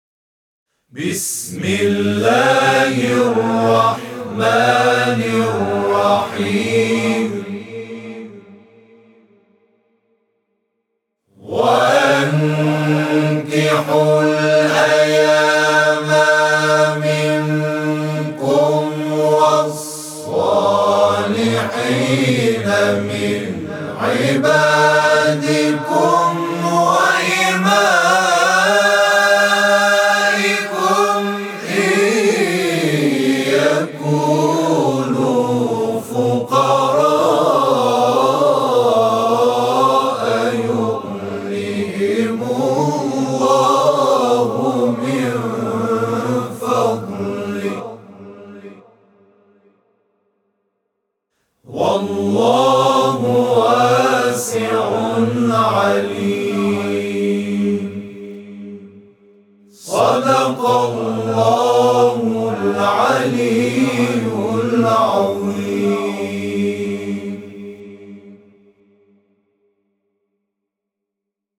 صوت همخوانی آیه 32 سوره نور  از سوی گروه همخوانی «محمد رسول‌الله(ص)»